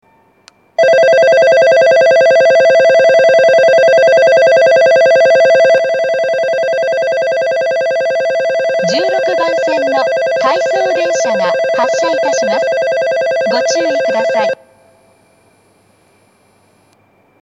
標準的な音程の発車ベルを使用していますが、１５・１６番線の発車ベルは音程が低いです。
１６番線発車ベル 回送電車の放送です。